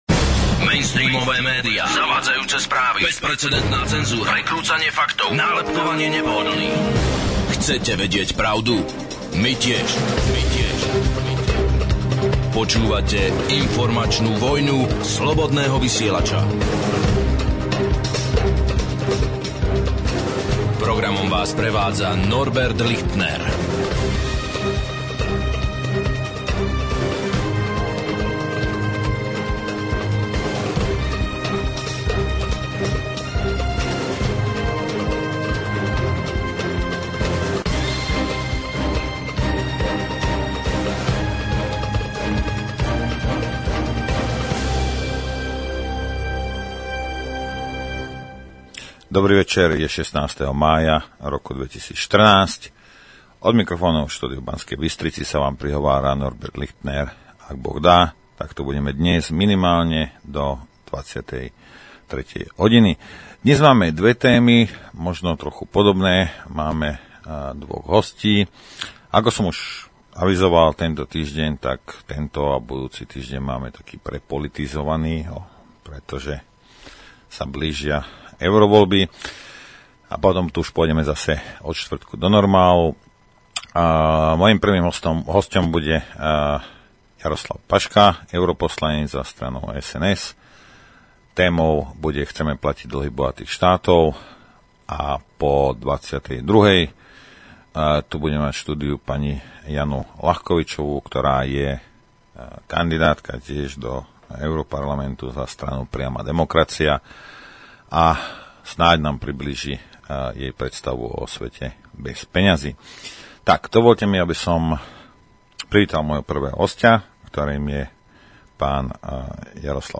1) Chceme platiť dlhy bohatých štátov? Hosť: Jaroslav Paška - europoslanec 2) Svet bez peňazí.